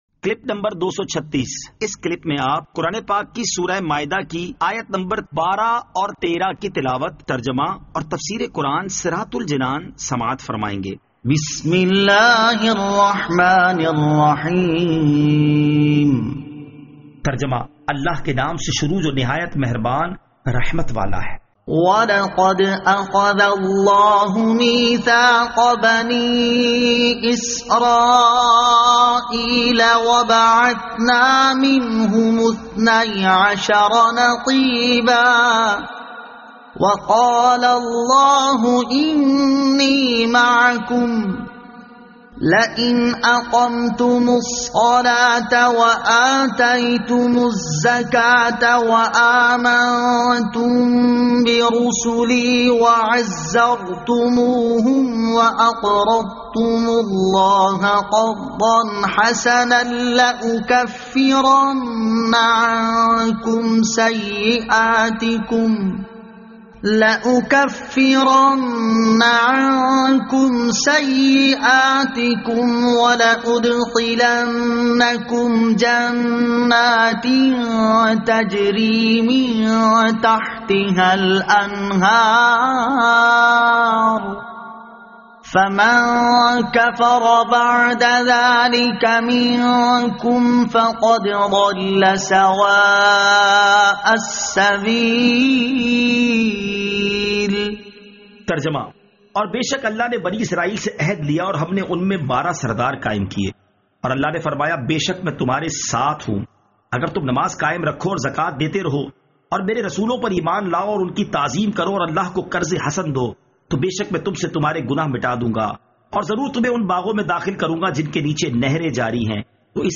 Surah Al-Maidah Ayat 12 To 13 Tilawat , Tarjama , Tafseer